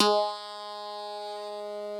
genesis_bass_044.wav